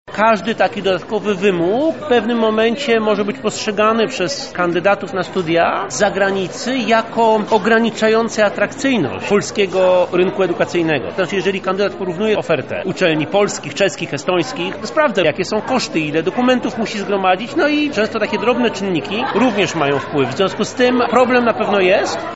Konferencja pod tytułem „Studenci zagraniczni w Lublinie – potencjał doświadczeń i nowe perspektywy”, która odbyła się 5 grudnia na Wydziale Politologii i Dziennikarstwa UMCS była polem do dyskusji na temat wad i zalet przyjmowania obcokrajowców przez polskie uczelnie.